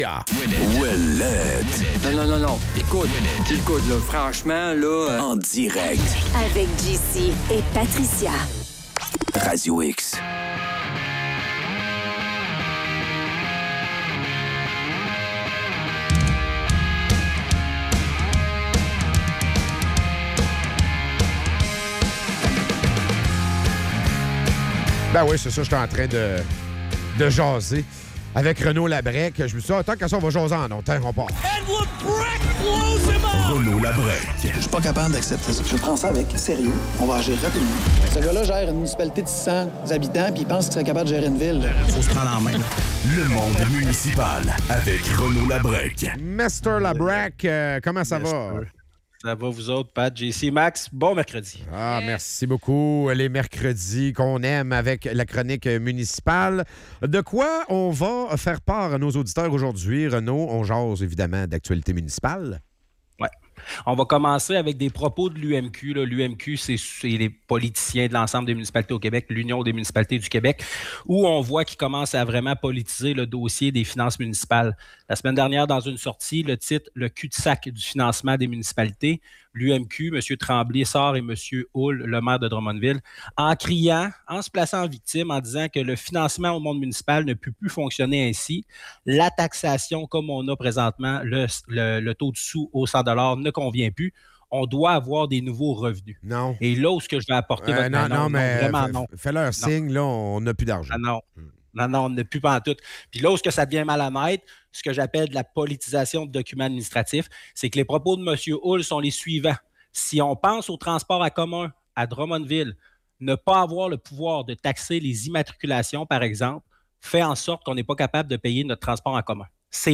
En chronique